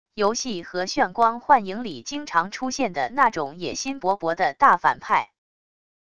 游戏和炫光幻影里经常出现的那种野心勃勃的大反派wav音频